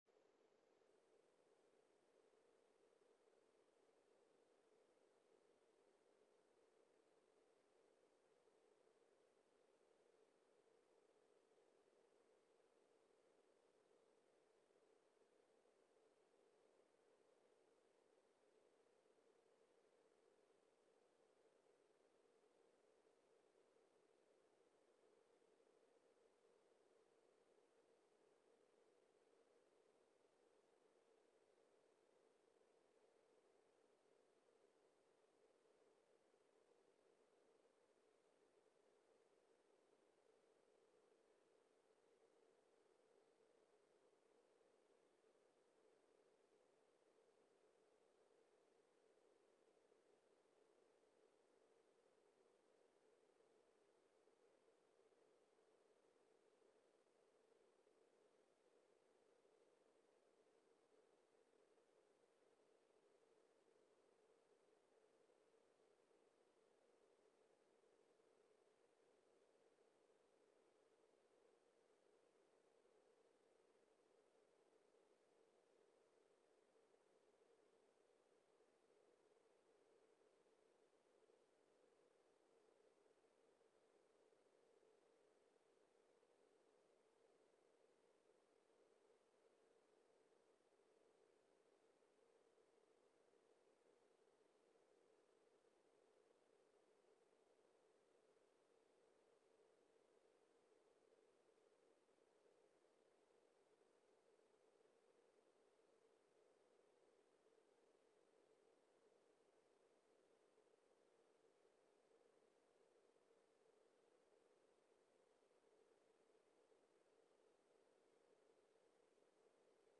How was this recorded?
LIVE Morning Worship Service - Faith 101: The Last Things